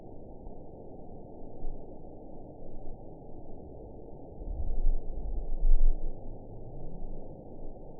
event 917891 date 04/19/23 time 22:41:28 GMT (1 year ago) score 6.67 location TSS-AB06 detected by nrw target species NRW annotations +NRW Spectrogram: Frequency (kHz) vs. Time (s) audio not available .wav